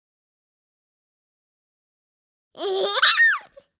tickle6.ogg